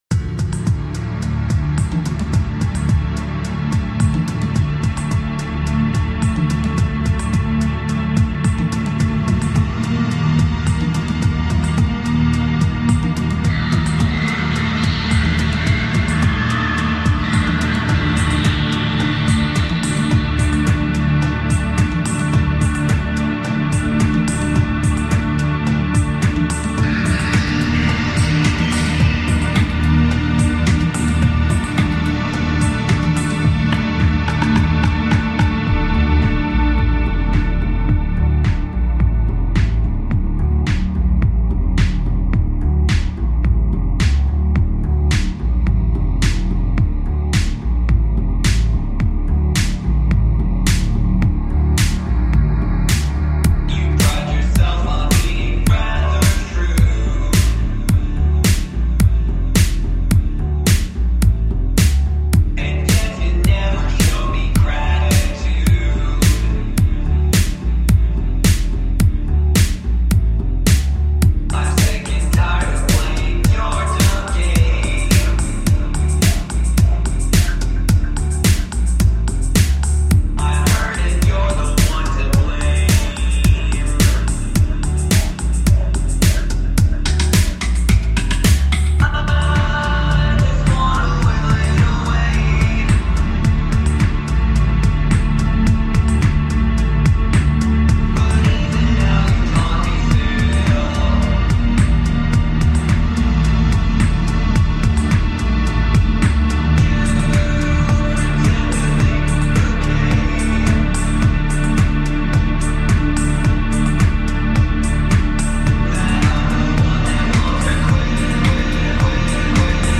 Genre: Psybient/Trance